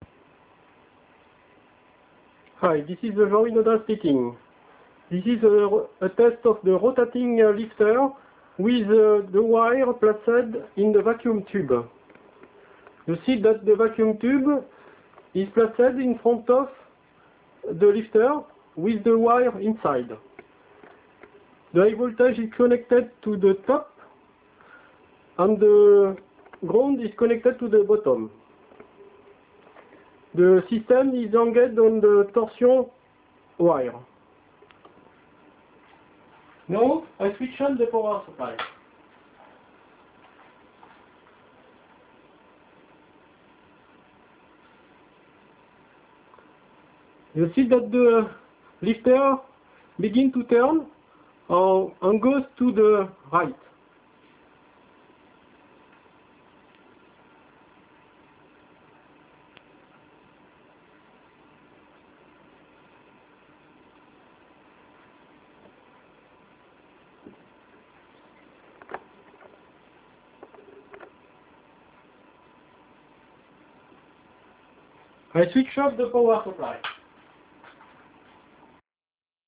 There is neither corona effect nor ion wind effect in the vacuum tube, there is no hissing sound and the lifter moves very silently.